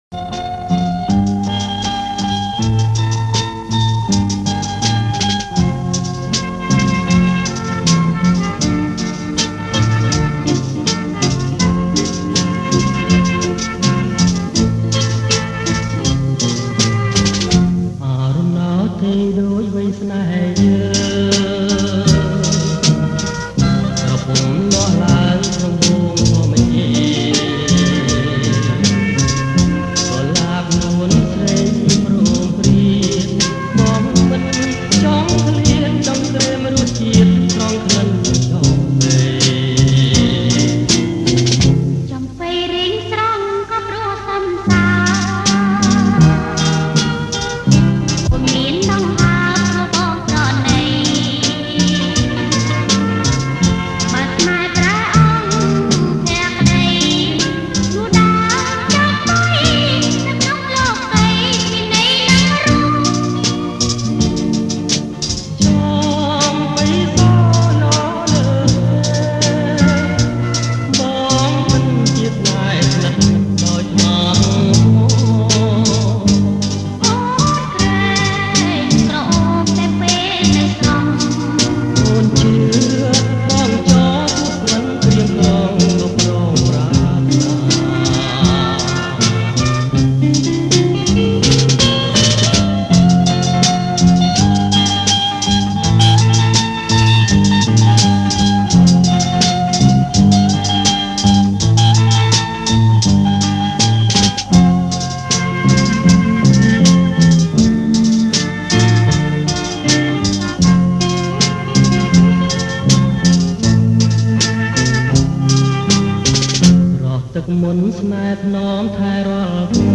ប្រគំជាចង្វាក់ Bolero